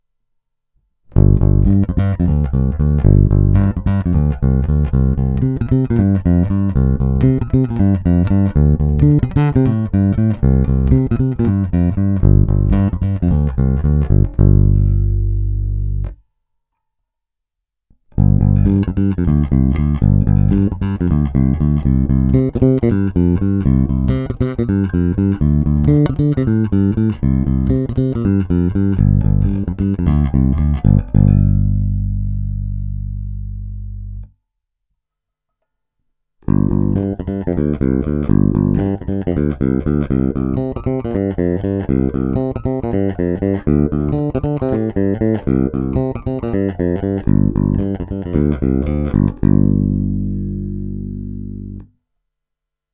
Zvuk je poměrně zvonivý, vrčí, výšek je dost.
Není-li uvedeno jinak, následující nahrávky jsou provedeny rovnou do zvukové karty s plně otevřenou tónovou clonou. Nahrávky jsou jen normalizovány, jinak ponechány bez úprav.